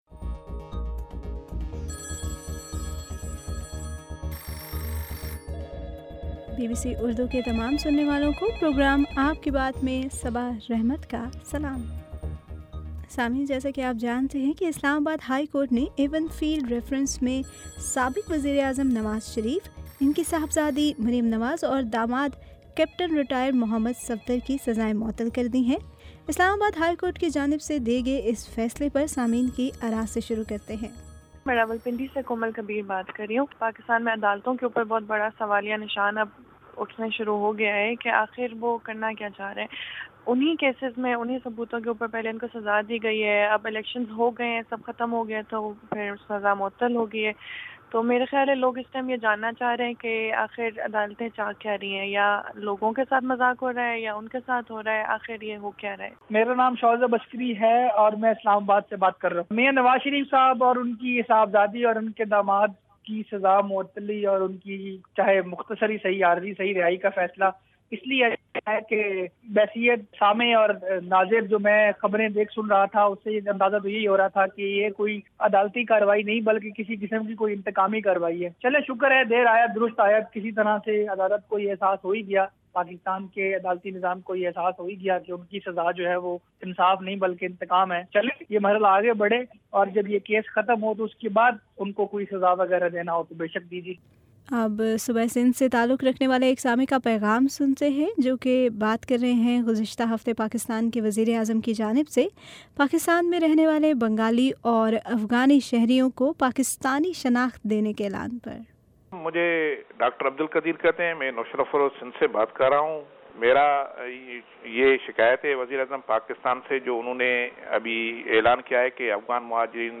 آپ کے صوتی پیغامات پر ، مبنی پروگرام ” آپ کی بات ٌ